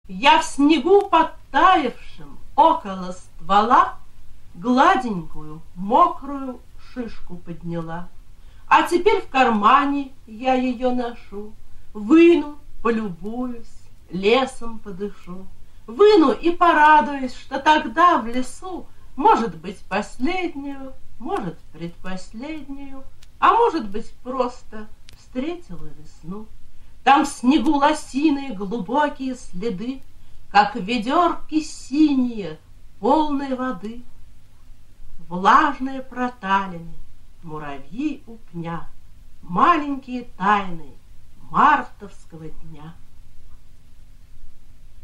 veronika-tushnova-shishka-chitaet-avtor